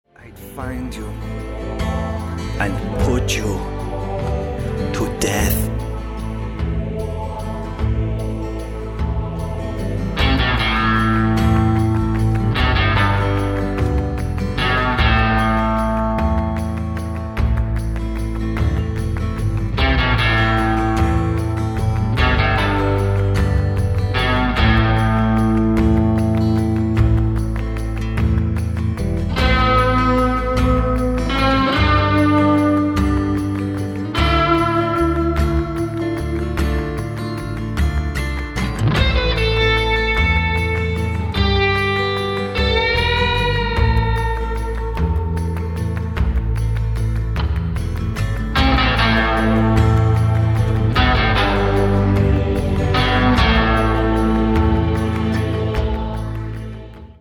• Качество: 160, Stereo
ковбойская тема